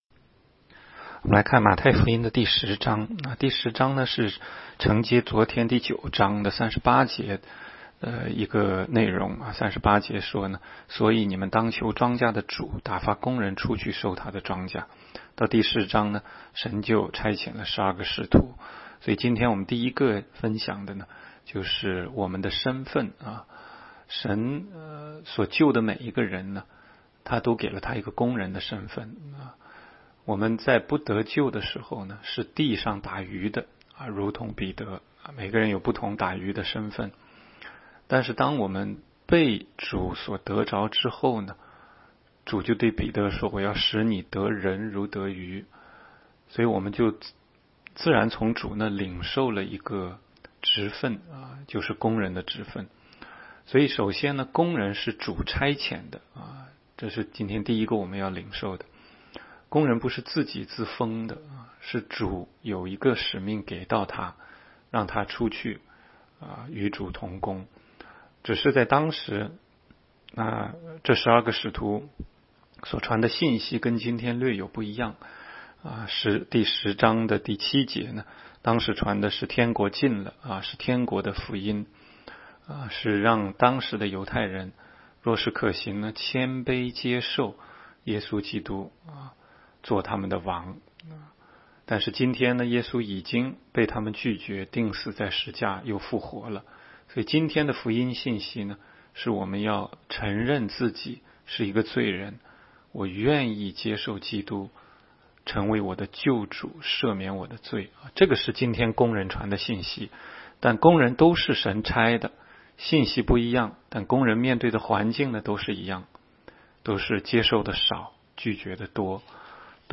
16街讲道录音 - 每日读经-《马太福音》10章
每日读经